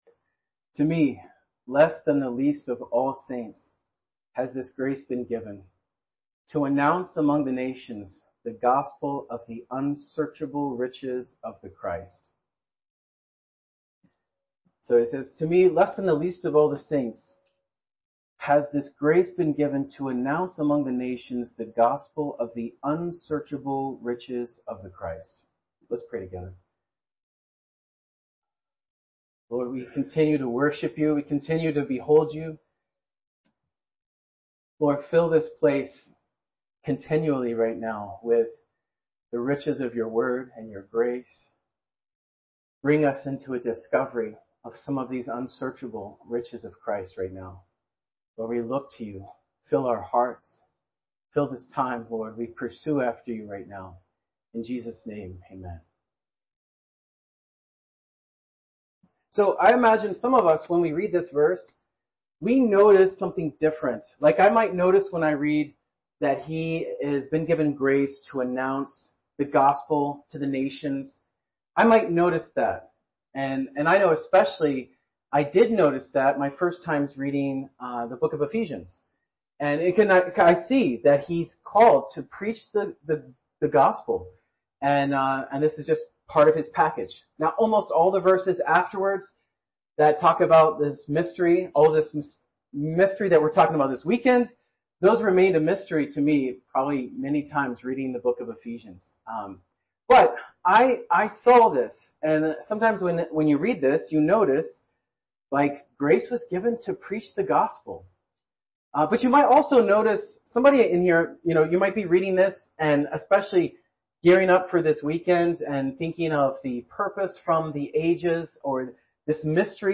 A collection of Christ focused messages published by the Christian Testimony Ministry in Richmond, VA.
Winter Youth Conference